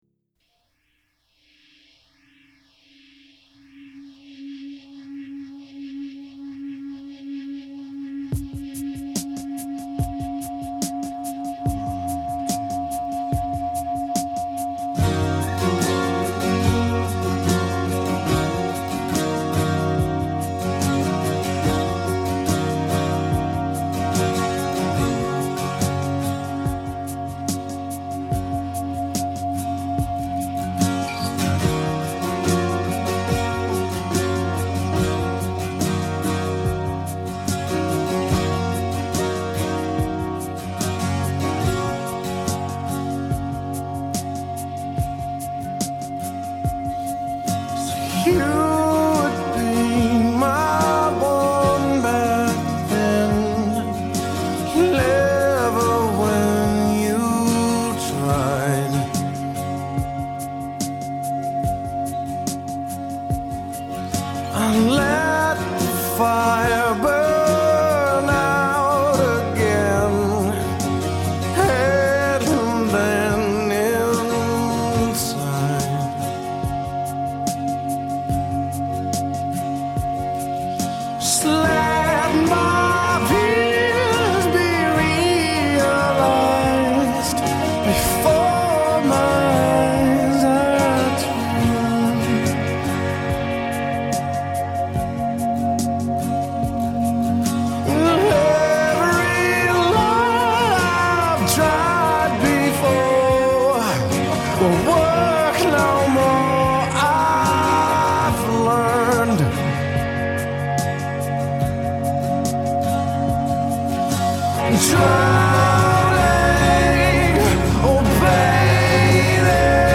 Tags1990s 1997 Canada Rock